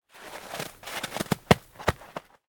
snowball.ogg